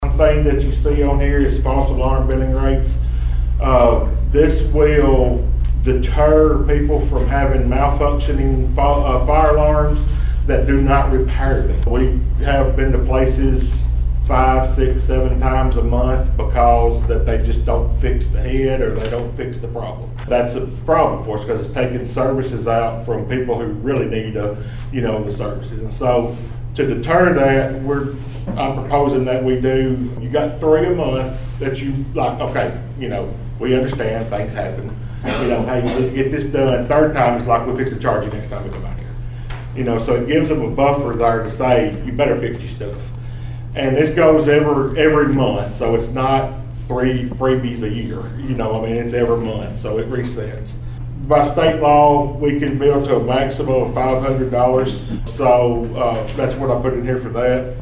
Martin Fire Chief Jamie Summers explains that false fire alarm calls happen often and take time away from real emergencies.